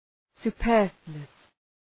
Προφορά
{sʋ’pɜ:rflu:əs}